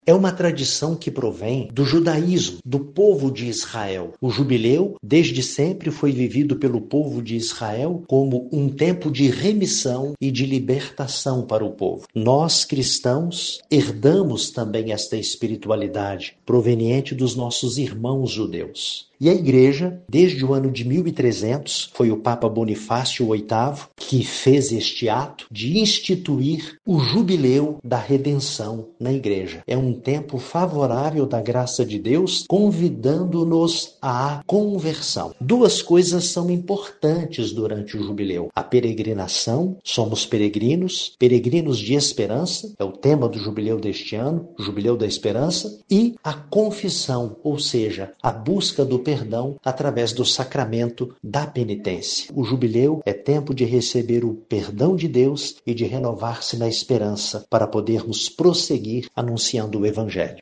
Dom Geovane Luis da Silva, bispo da Diocese de Divinópolis fala um pouco sobre a importância da celebração do Jubileu, uma tradição antiga da Igreja: